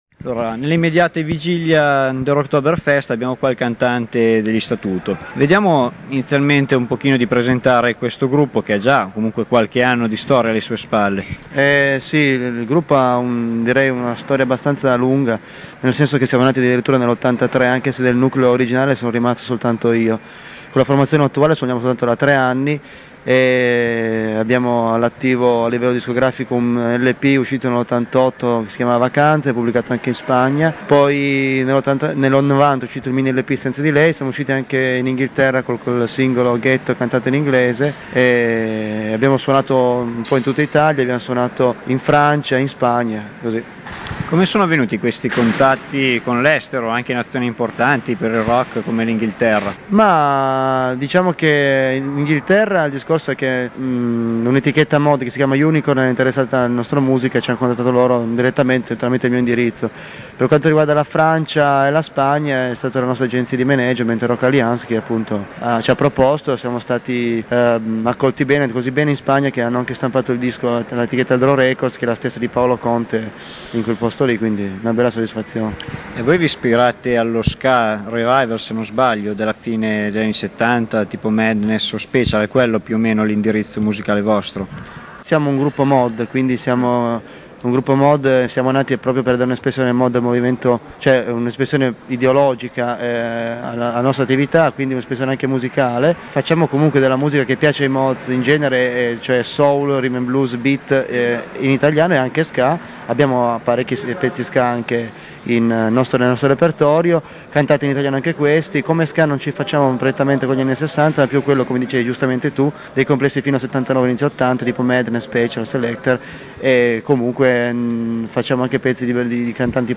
• Rocktober Fest (Capannone APA del Quartiere Orti, Alessandria)
l'intervista